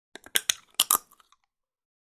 Звуки фисташек
Здесь собраны уникальные записи: от мягкого шелеста скорлупы до насыщенного хруста при разламывании.
Хруст скорлупы под зубами